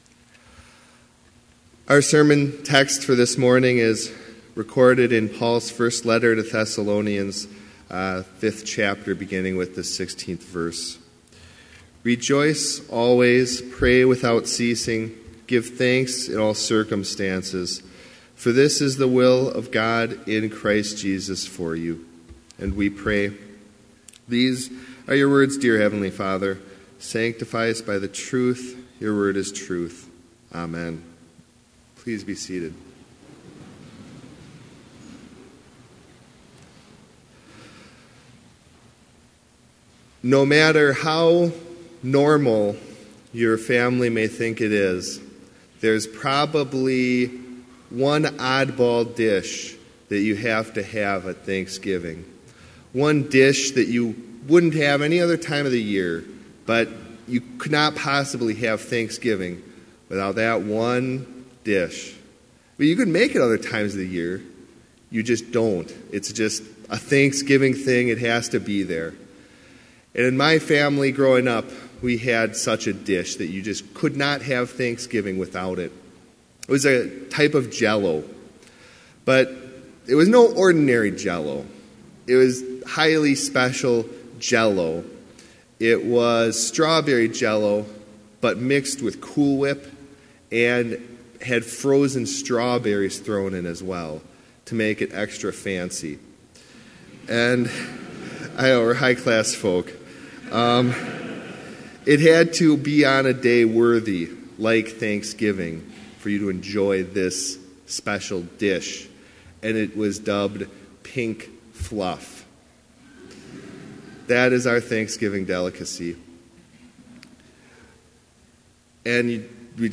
Complete service audio for Chapel - November 22, 2017